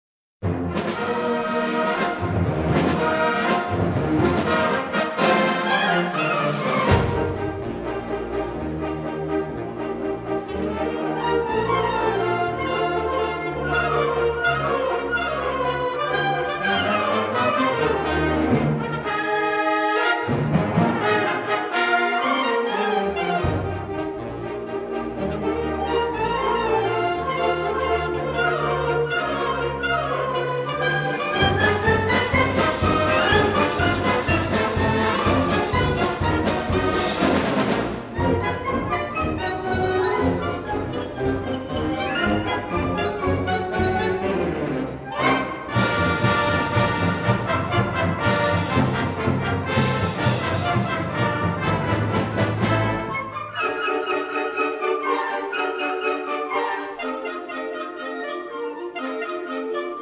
perform here live in their send off concert at home